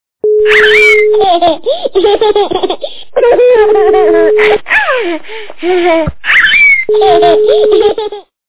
» Звуки » Смешные » Веселый - детский смех
При прослушивании Веселый - детский смех качество понижено и присутствуют гудки.